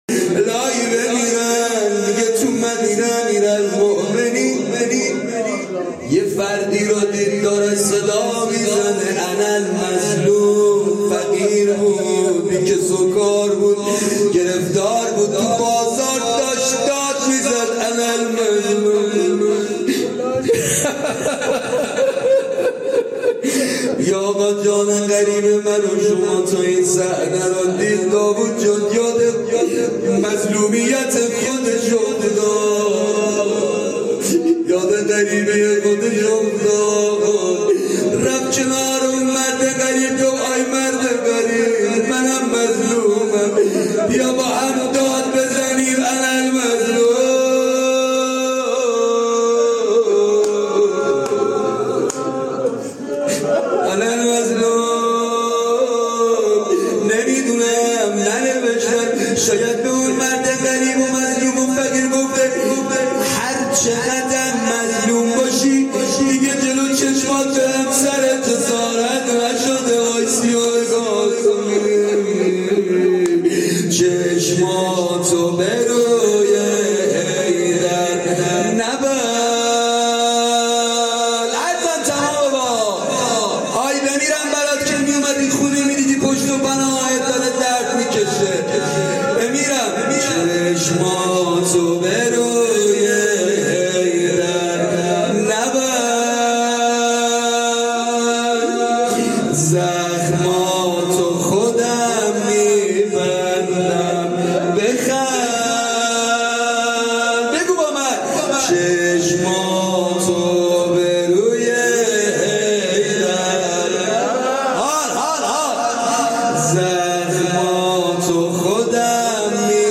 جلسات هفتگی فاطمیه